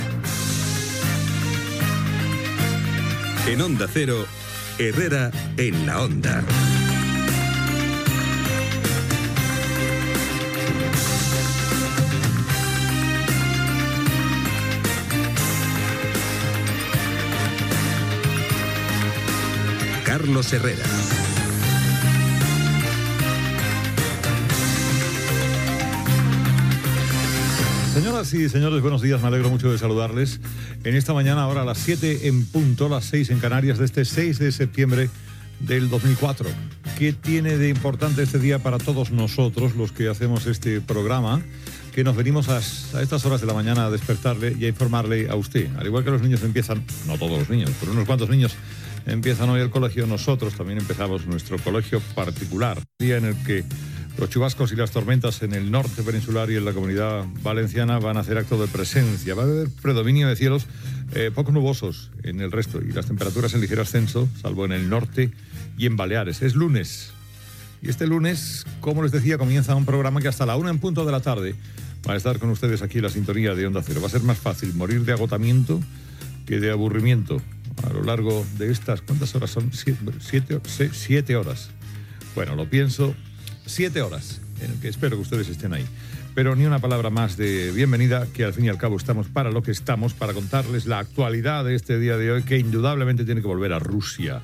Careta del programa, hora, data, salutació de la primera edició del programa en horari matinal a l'inici de la temporada 2004/2005.